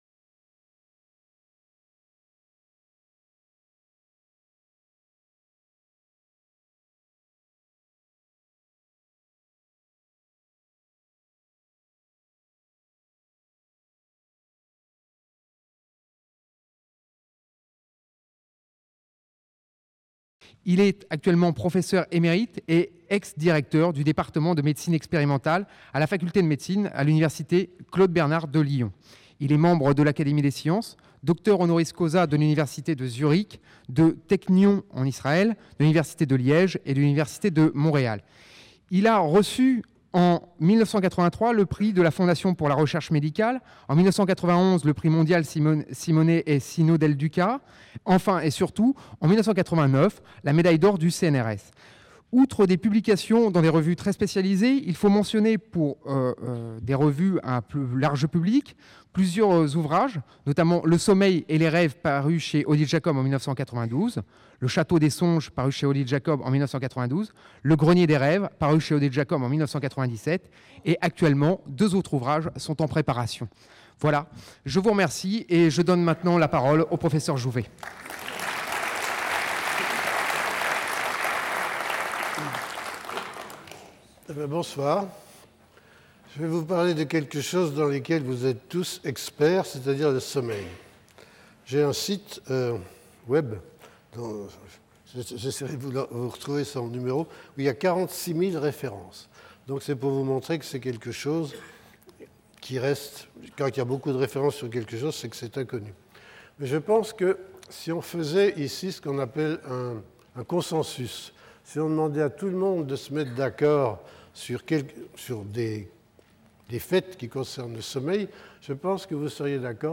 Conférence du 4 février par Michel Jouvet. Le sommeil de l'homme est l'aboutissement d'une très longue évolution phylogénétique qui a intégré les mécanismes suivants : 1) L'"invention" chez les êtres pluricellulaires de mécanismes "d'homéostasie prédictive".